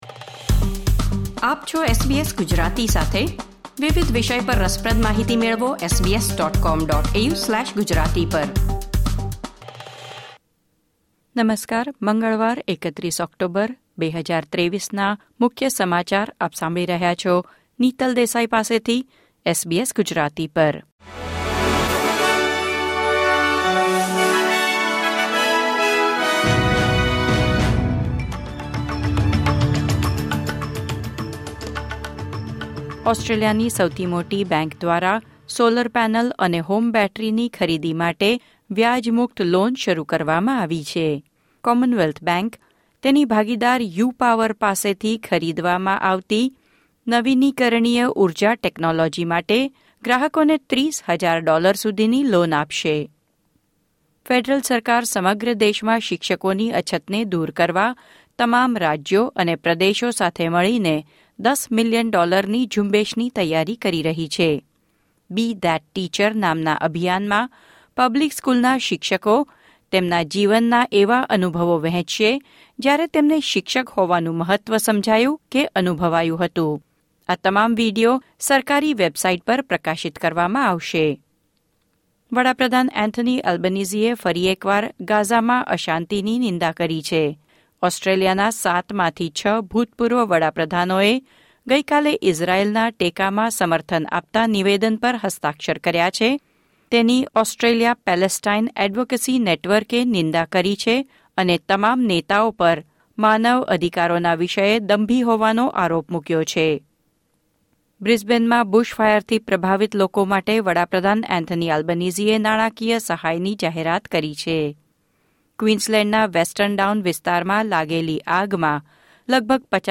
SBS Gujarati News Bulletin 31 October 2023